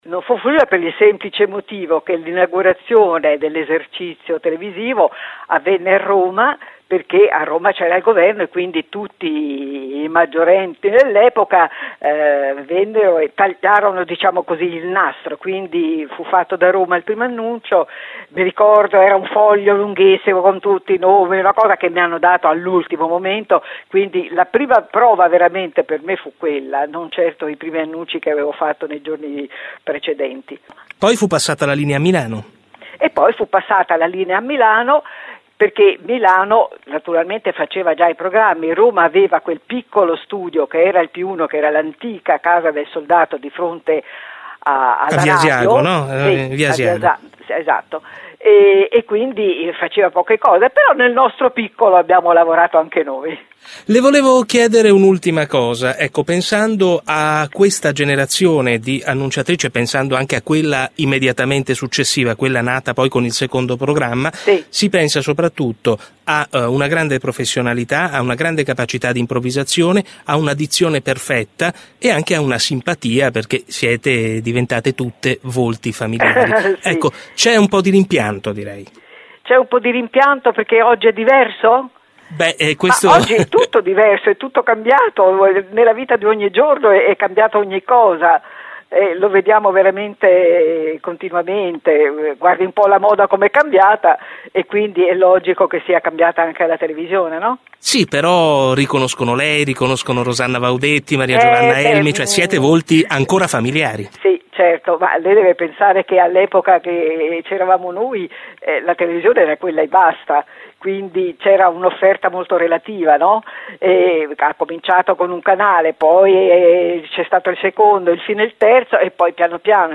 Ripropongo qui l’intervista che ormai credo risalga a 14 anni fa, sempre orgoglioso di averla realizzata.